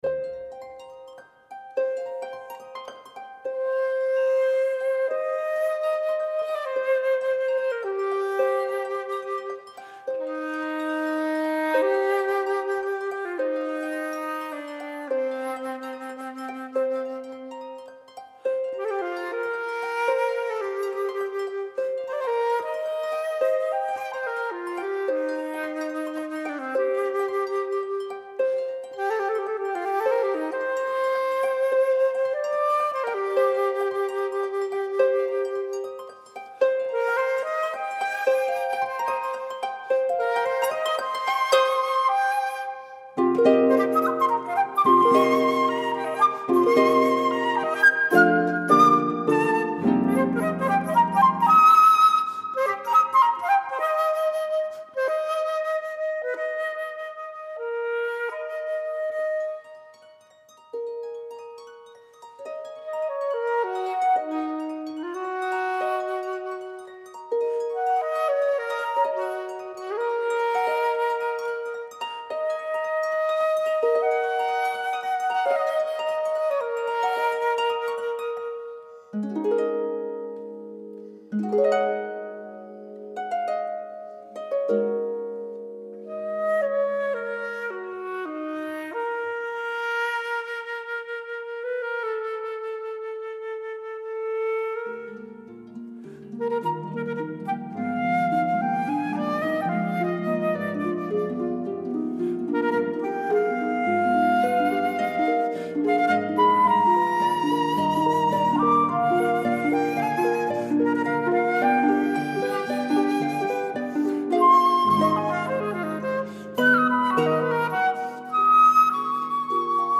Incontro con il flautista